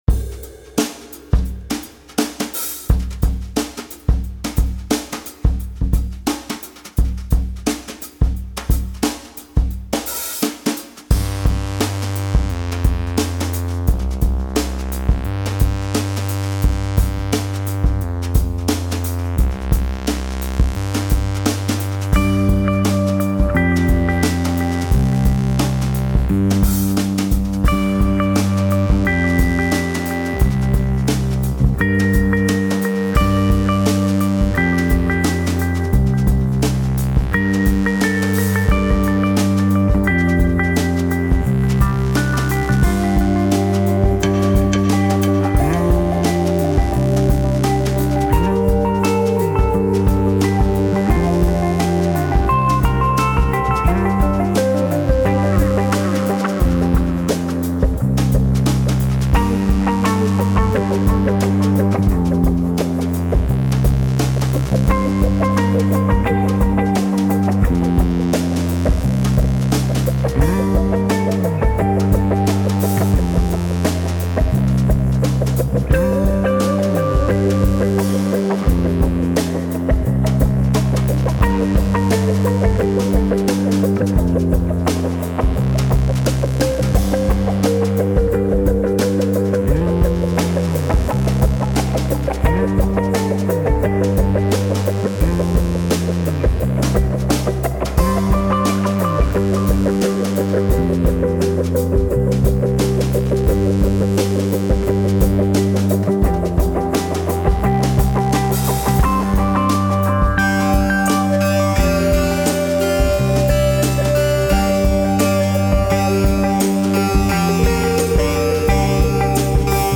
progressive rock mixed with psychedelic and post roots
completely vocals-free